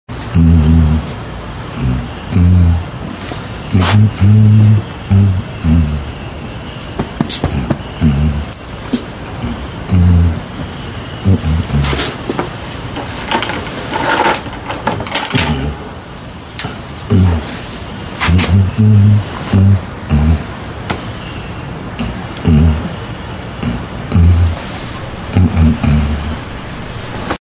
音源、ちょっと聴きとりずらいかもしれません）と変わっているのです。